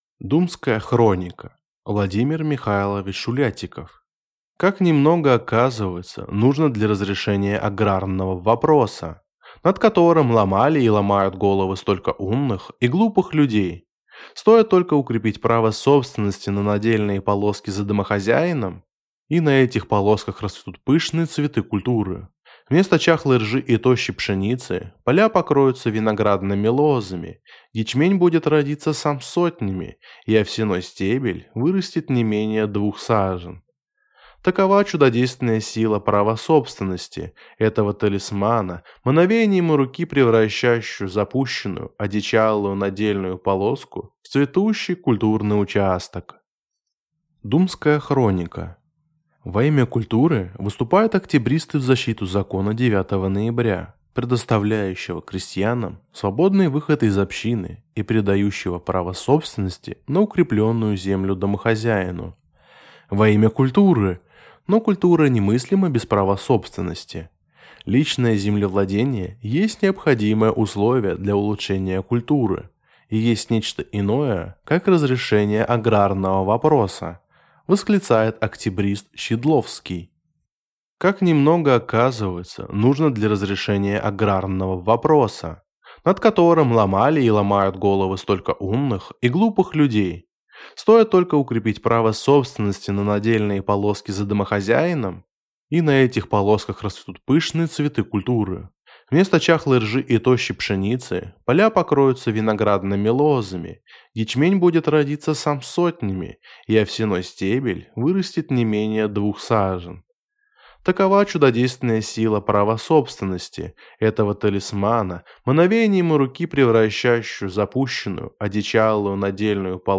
Аудиокнига «Думская хроника» | Библиотека аудиокниг